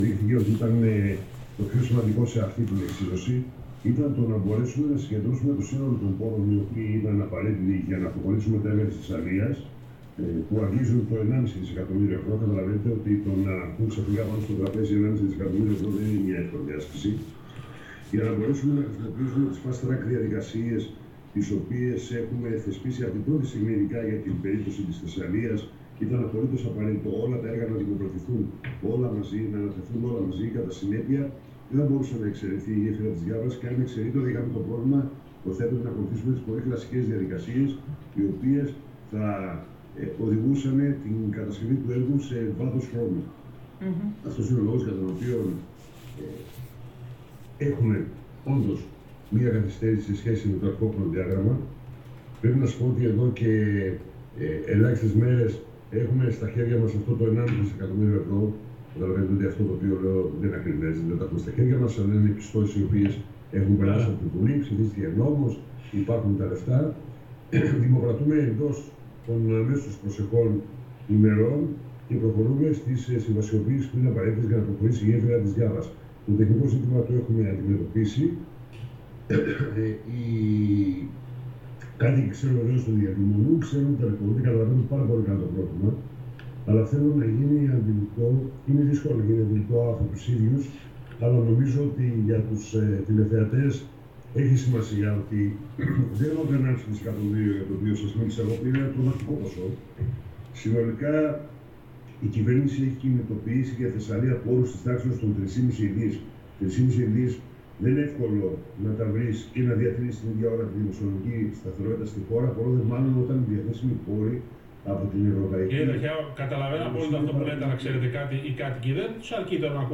Σήμερα στην πρωινή εκπομπή της ΕΡΤ ο αρμόδιος Υφυπουργός για θέματα Υποδομών Νίκος Ταχιάος έχασε κυριολεκτικά τα λόγια του όταν οι δημοσιογράφοι του ζητούσαν χρονοδιάγραμμα λέγοντας, ανερυθρίαστα μάλιστα, πως δεν μπορεί να δώσει ούτε να υποσχεθεί πως θα μπει μπουλντούζα τον Μάρτιο.